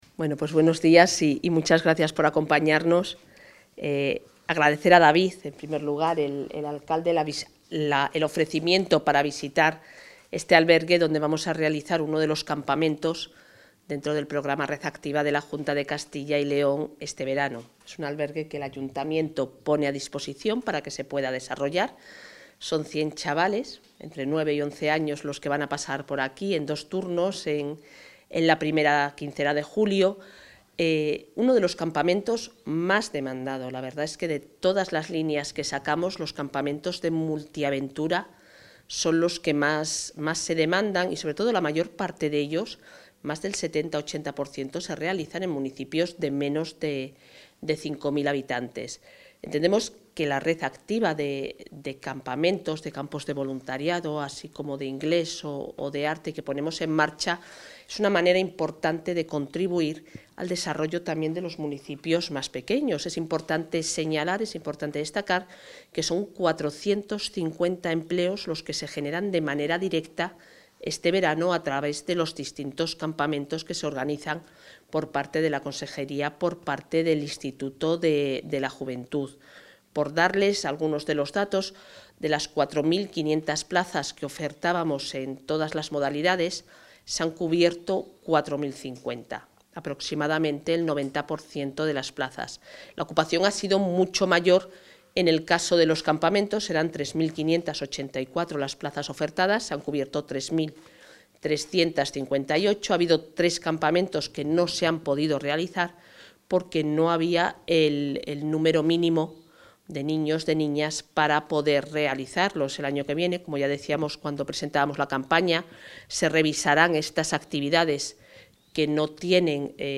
Intervención de la consejera de Familia e Igualdad de Oportunidades.